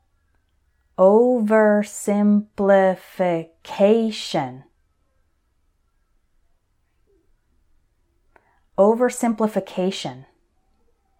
So for these words I’ll say them once slowly and once normally, so you can repeat both times.
o – ver – sim – pli – fi – CA – tion………. oversimplification